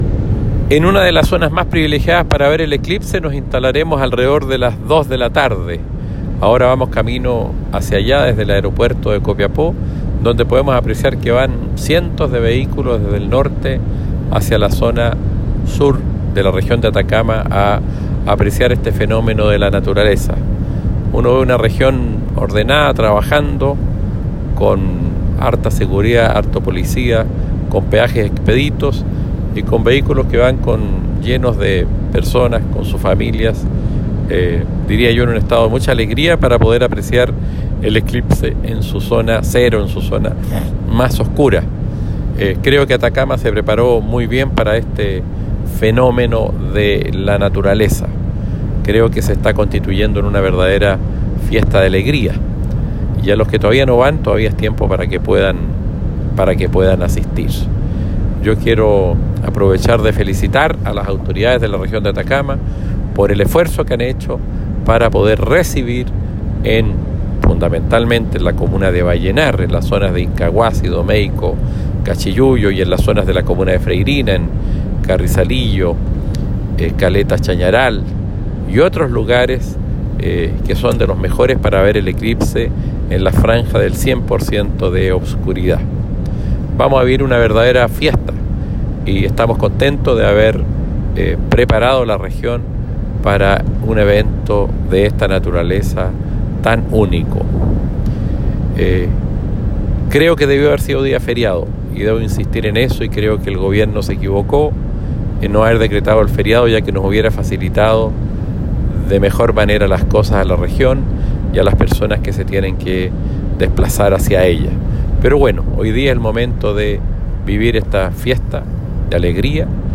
CUÑA-ECLIPSE.m4a